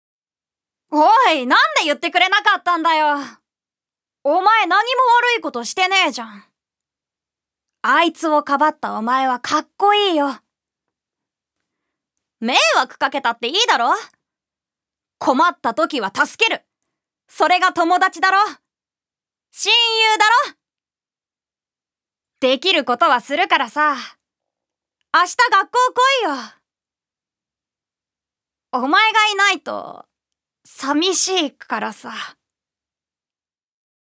ジュニア：女性
セリフ５